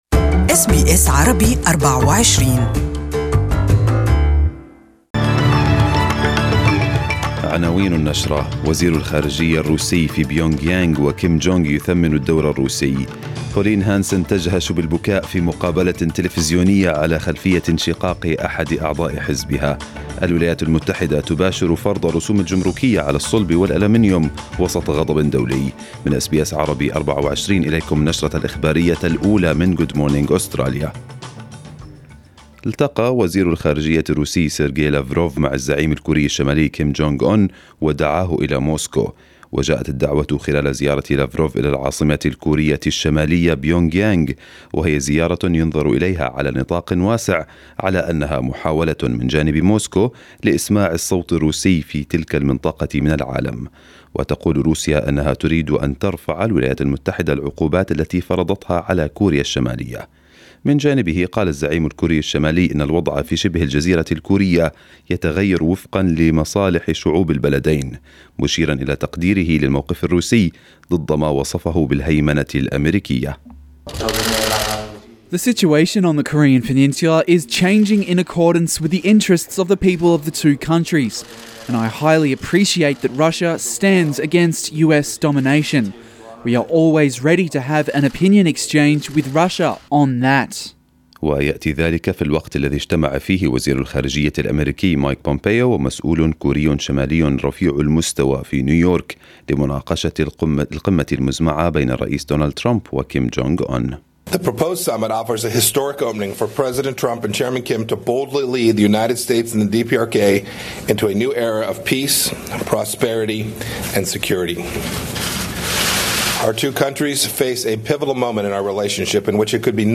Arabic News Bulletin 01/06/2018